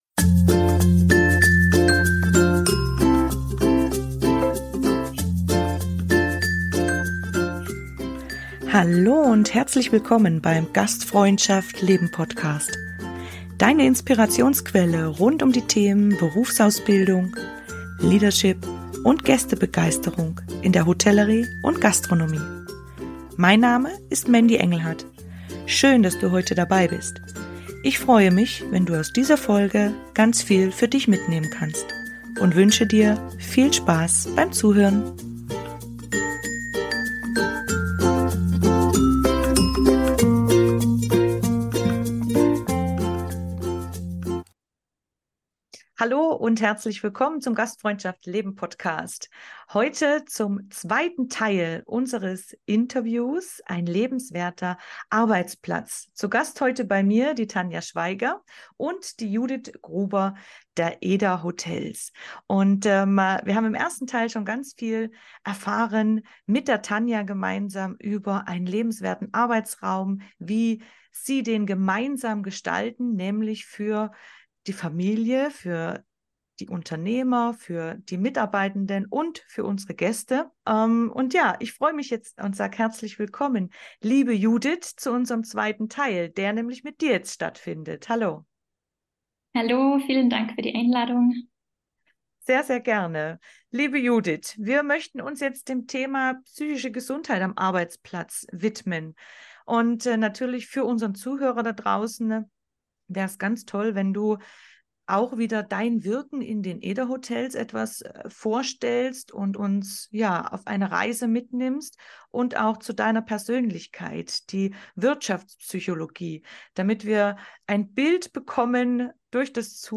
In unserem wertvollen Gespräch erwartet dich Aufklärung, Information und Inspiration.